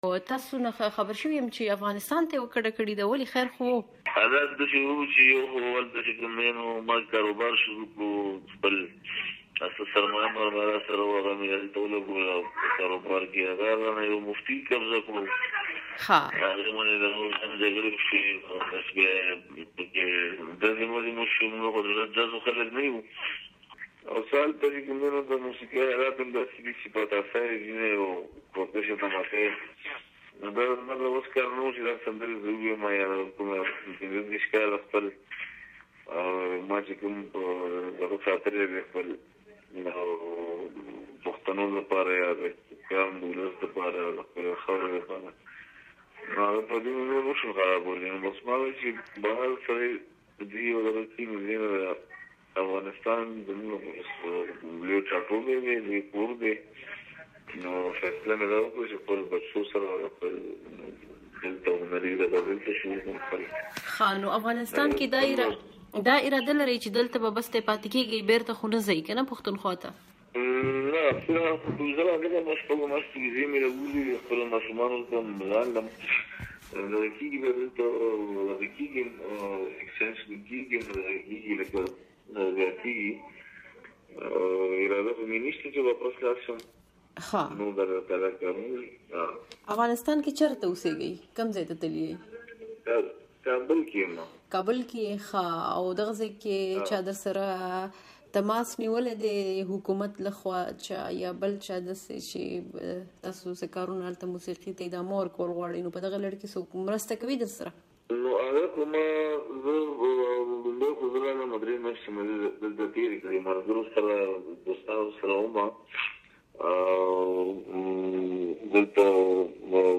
مرکې
د گلزارعالم سره مرکه